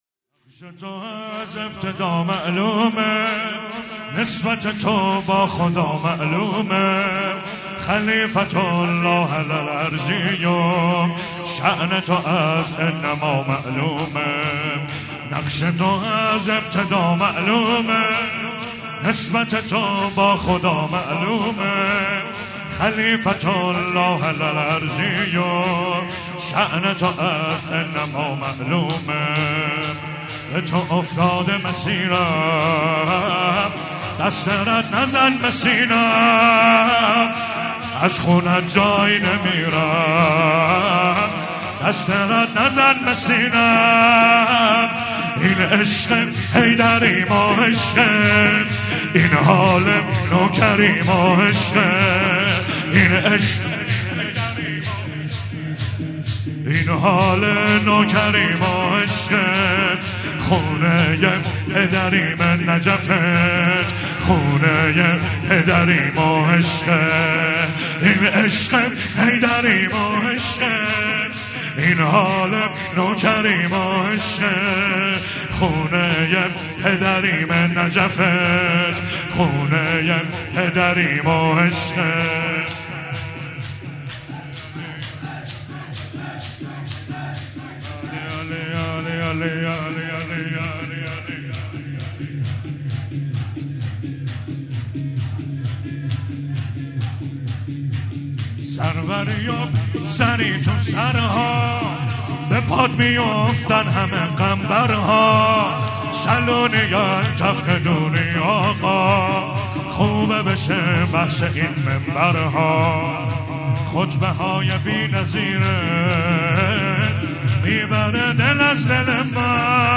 شهادت امام صادق علیه السلام 96 - شور - نقش تو از ابتدا معلومه
شهادت امام صادق علیه السلام
شور مداحی